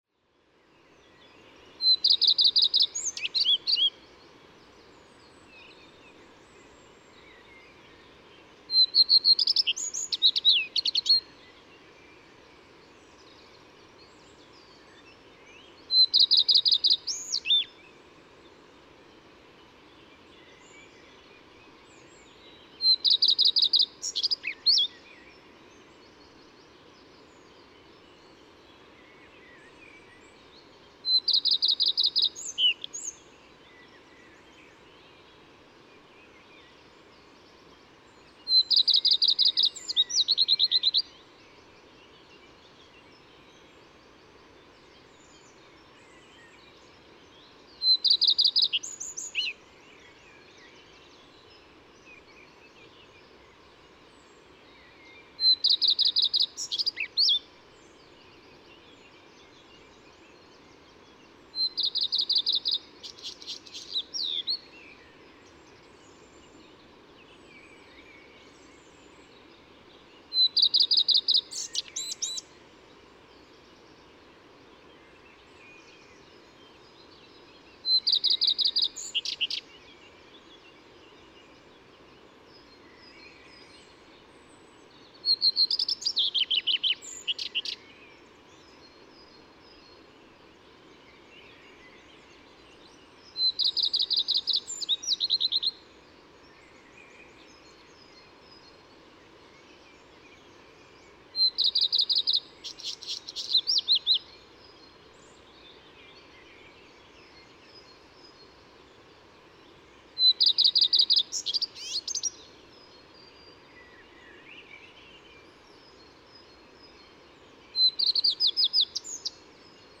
Krkonoše National Park
Greenish Warbler Phylloscopus trochiloides viridanus, male, song